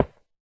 Minecraft / dig / stone2